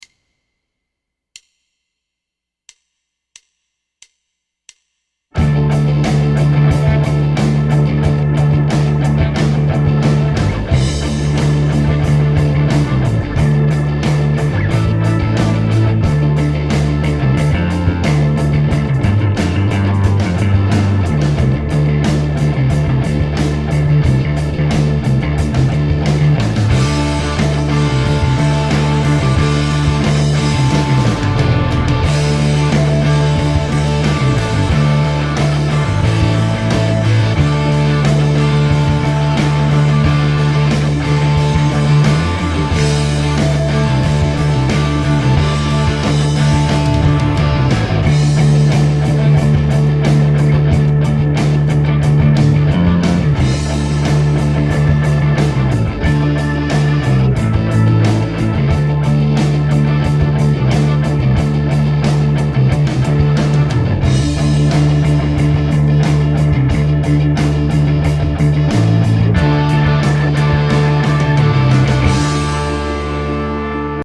Natürlich etwas vereinfacht und in einer leichteren Tonart.